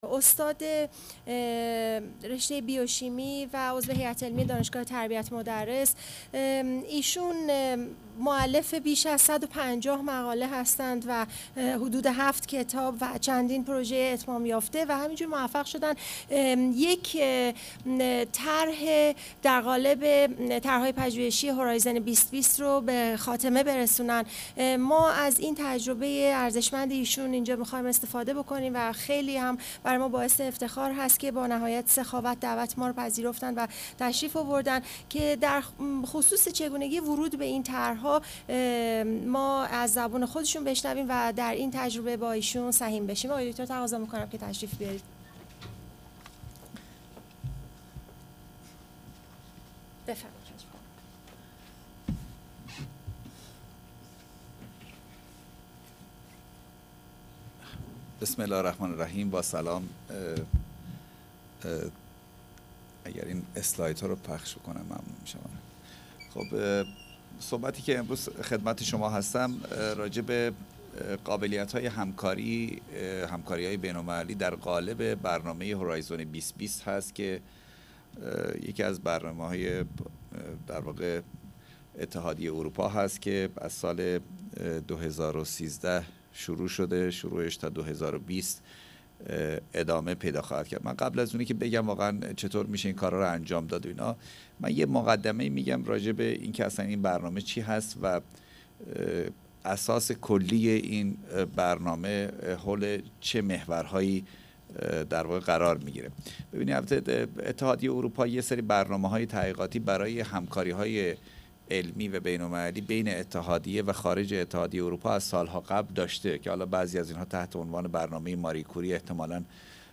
یکشنبه ۶ خرداد ماه ۹۷ ساعت ۱۰ الی ۱۲ مکان: سالن حکمت دانلود فایل صوتی